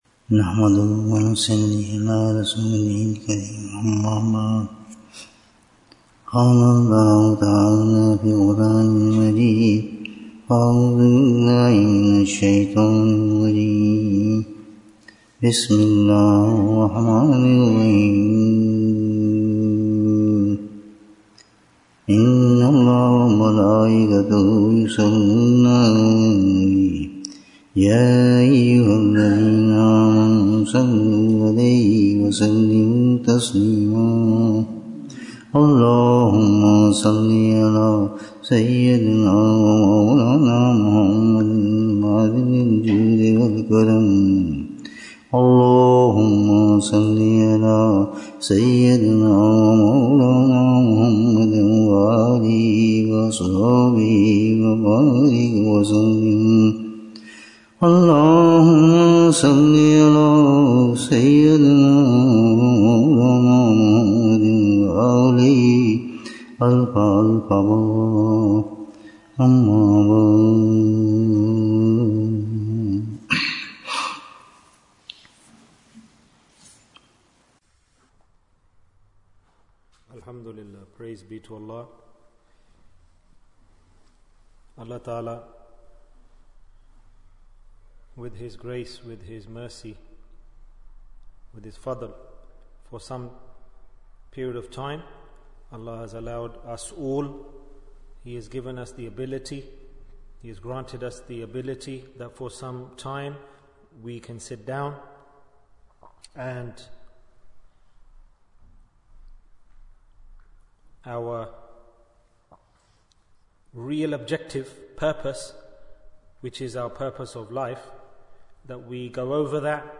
Bayan, 61 minutes8th August, 2024